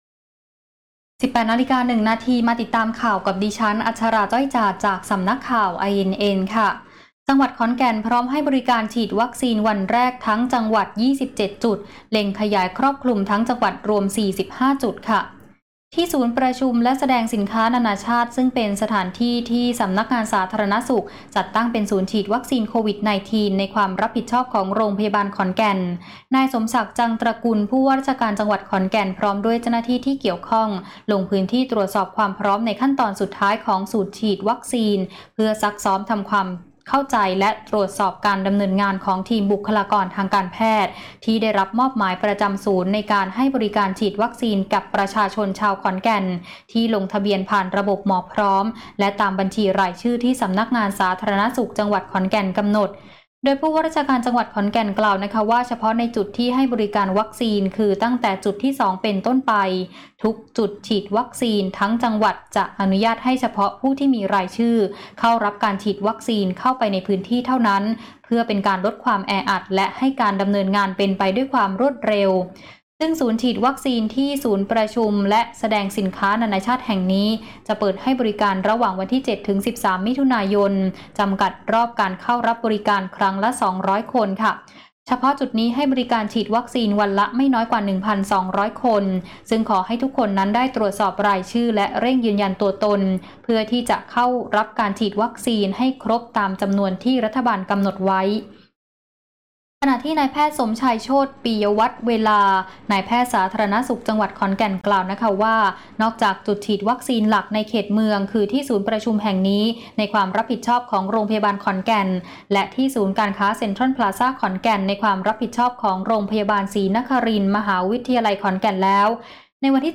คลิปข่าวต้นชั่วโมง
ข่าวต้นชั่วโมง 18.00 น.